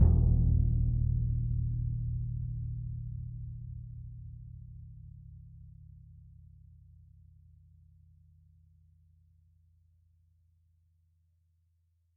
Orchestral Bass
bassdrum_roll_ff_rel.mp3